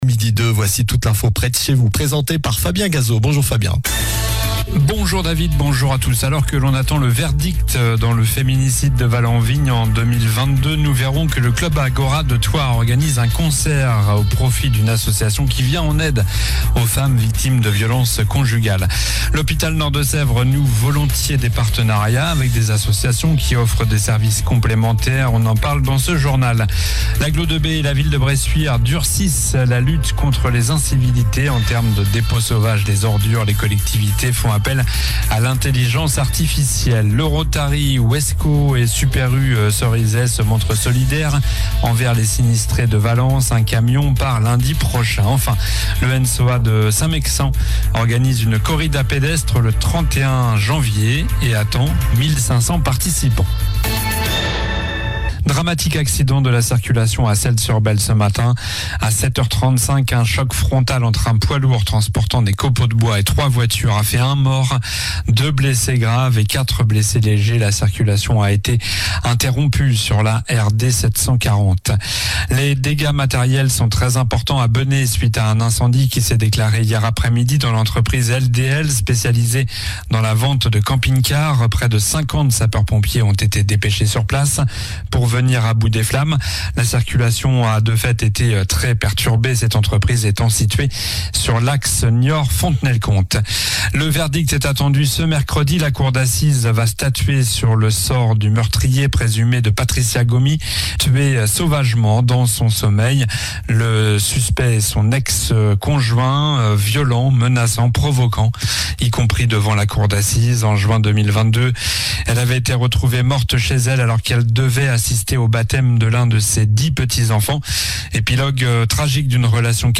Journal du mercredi 22 janvier (midi)